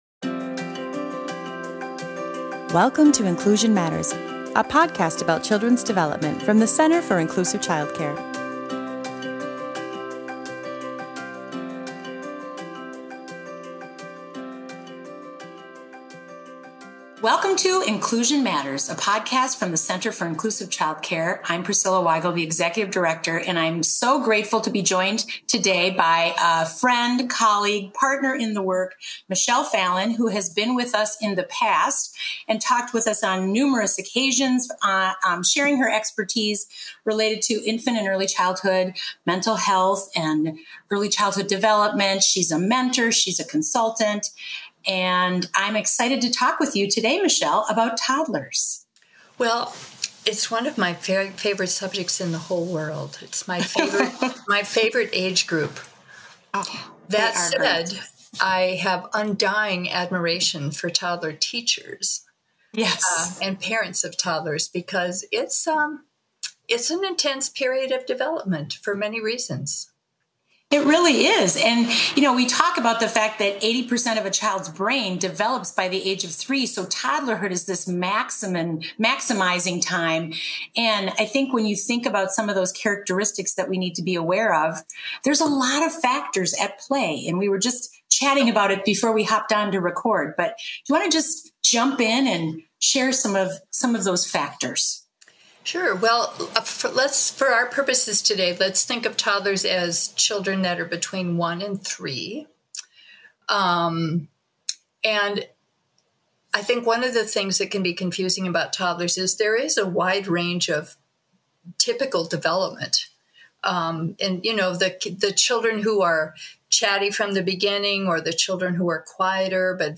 Early childhood experts discuss a wide array of topics of interest to early childhood professionals